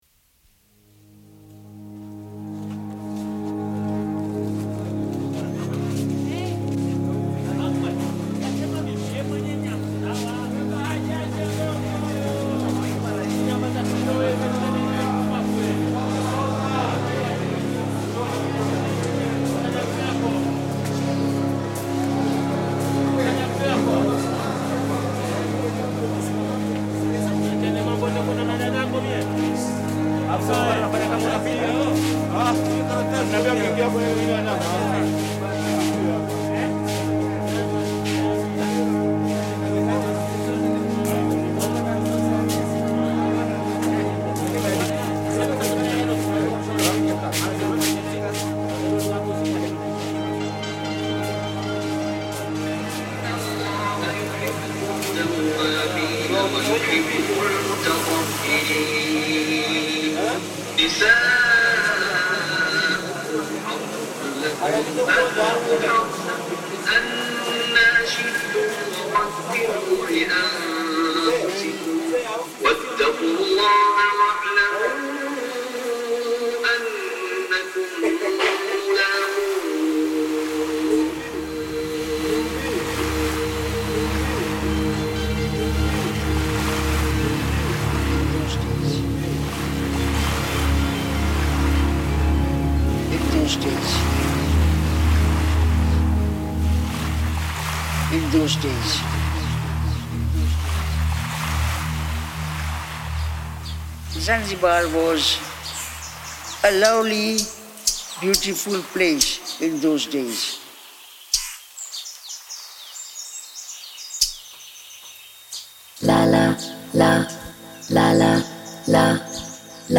Market in Stone Town, Zanzibar reimagined